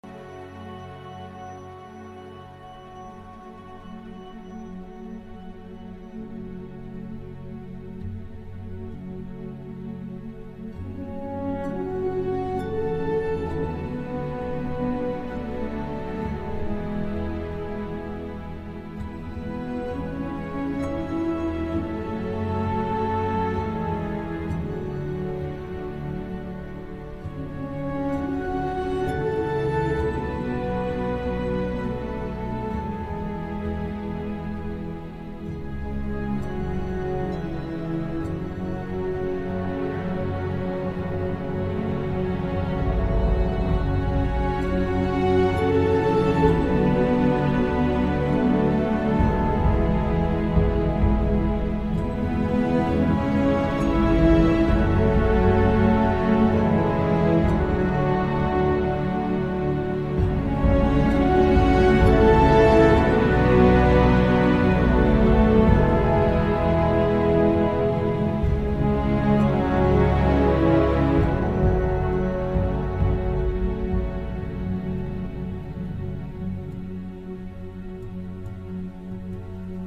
قطعه حماسی